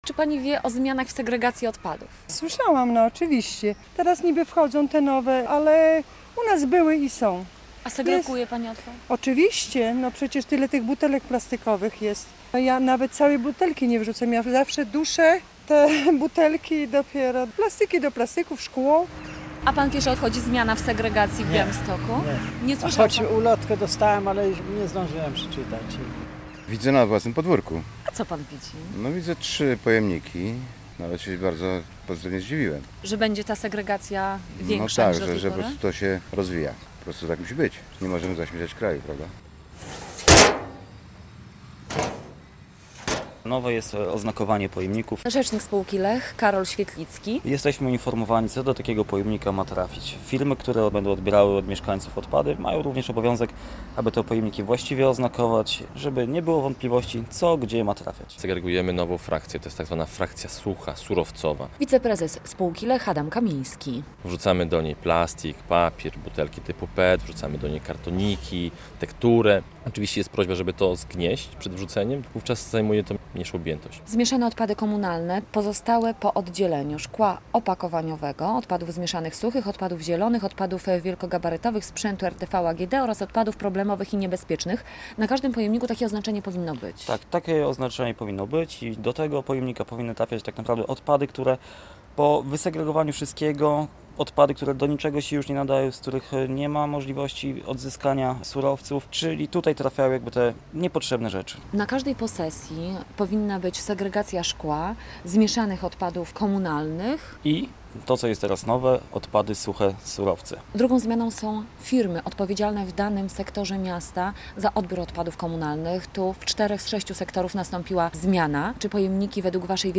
Segregacja po nowemu - relacja